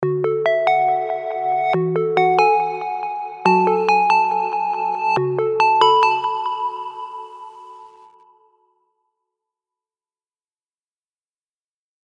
By moving duplicate parts around by half a bar, a 16th or even a whole bar, you can create interesting cross rhythms.
A way around this is to use high pass filtering and saturation to create a contrast between the edit and original audio.
Fades are introduced for smooth playback
The new smaller edits are added to the mix